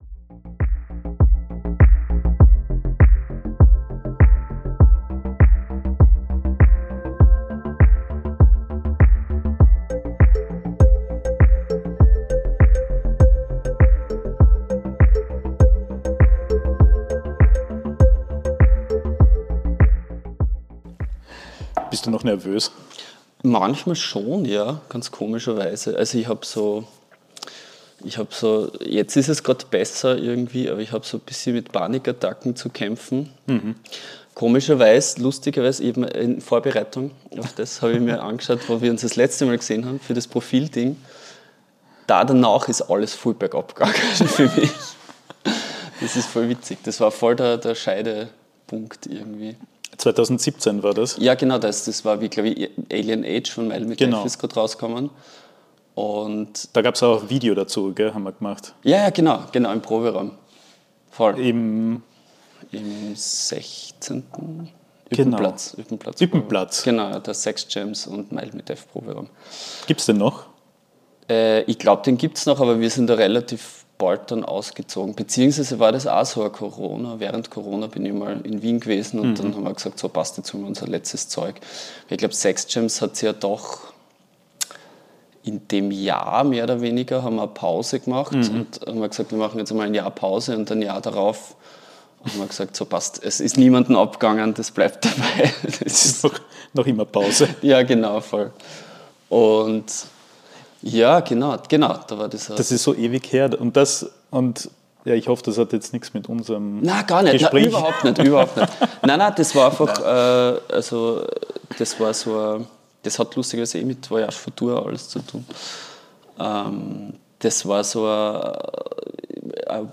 Ein Gespräch über Musik, Gesellschaft und die Kunst, immer wieder neue kreative Wege zu gehen.